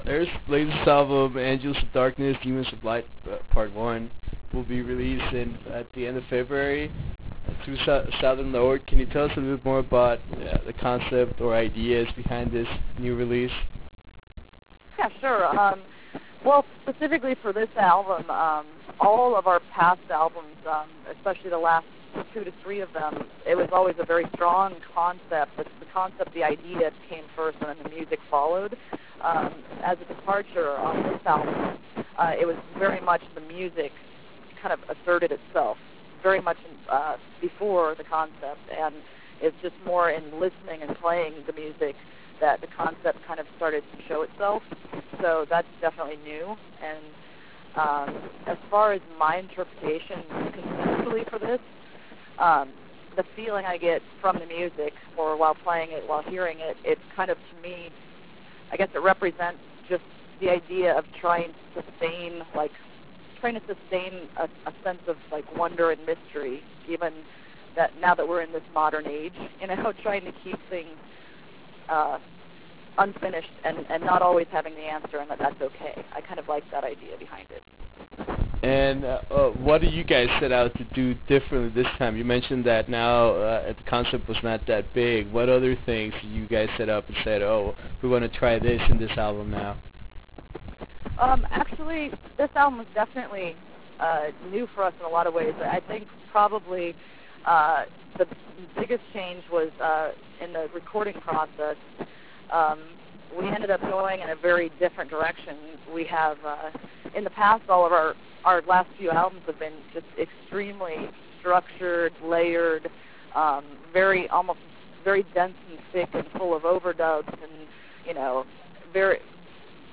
Interview with Earth - Adrienne Davies